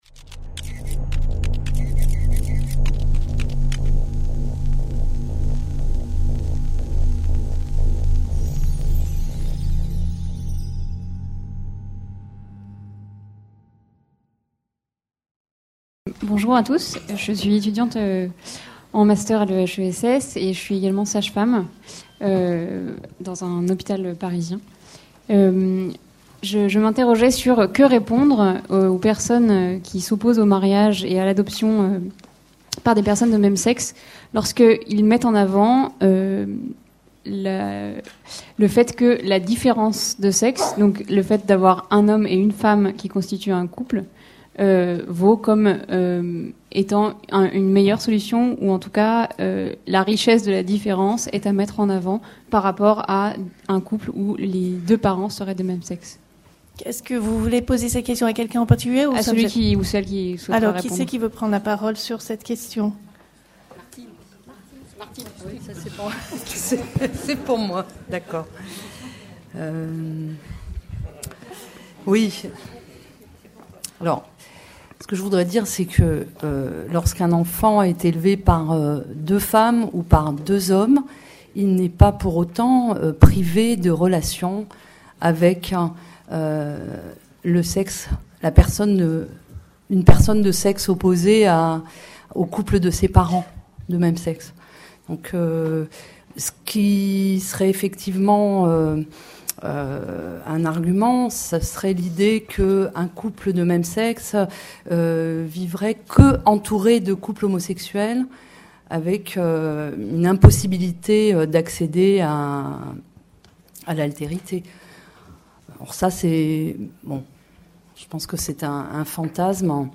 3/3 Mariage des personnes de même sexe et filiation : Le projet de loi au prisme des sciences sociales sequence 3: Questions du public | Canal U